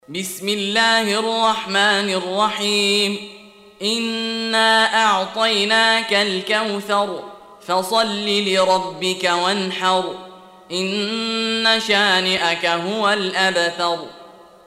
Surah Repeating تكرار السورة Download Surah حمّل السورة Reciting Murattalah Audio for 108. Surah Al-Kauthar سورة الكوثر N.B *Surah Includes Al-Basmalah Reciters Sequents تتابع التلاوات Reciters Repeats تكرار التلاوات